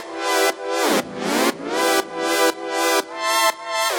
Index of /musicradar/french-house-chillout-samples/120bpm
FHC_Pad A_120-E.wav